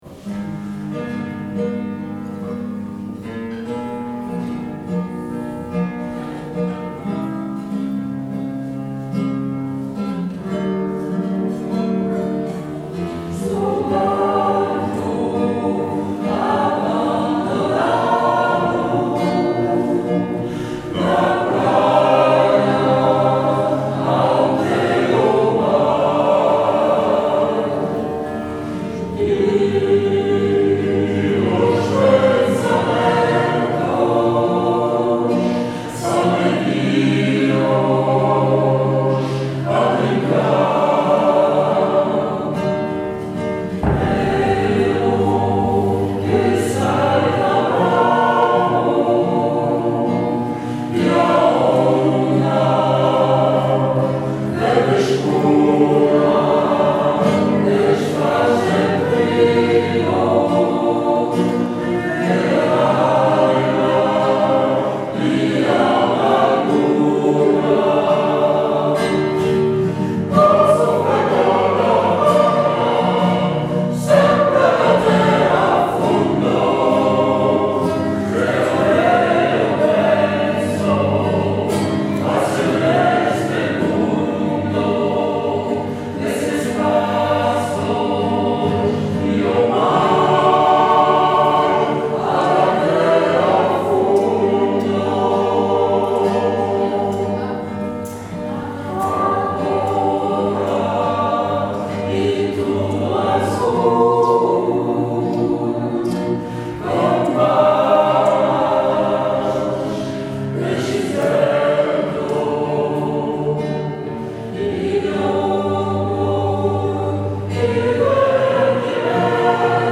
Pour vous, quelques titres chantés par Jacophonie en écoute sur le blog en attendant la reprise des ateliers et des concerts.
Sou barco – chant portugais